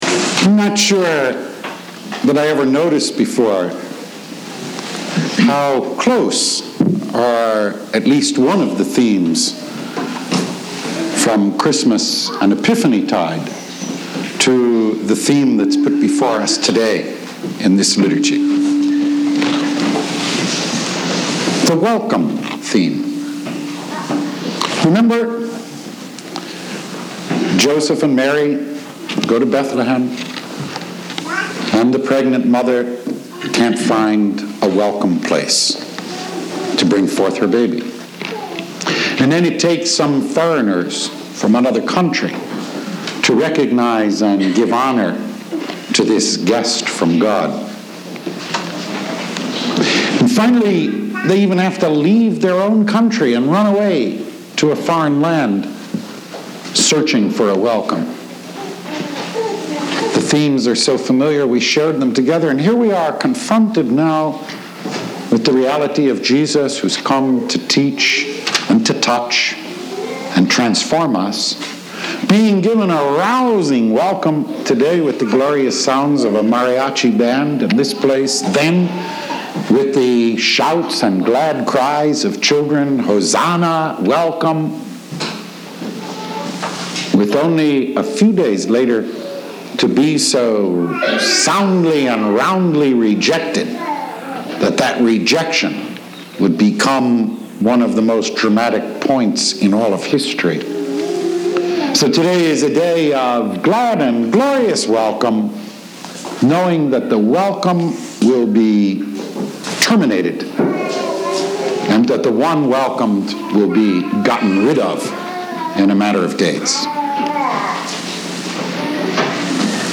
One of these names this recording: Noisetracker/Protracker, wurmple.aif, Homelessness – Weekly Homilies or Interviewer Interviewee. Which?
Homelessness – Weekly Homilies